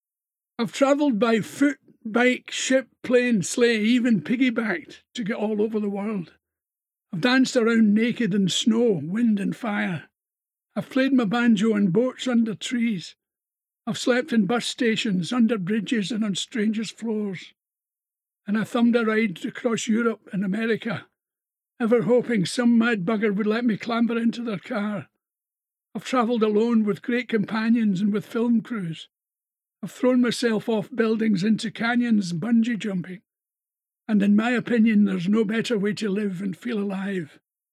Listen to a special message from Billy here: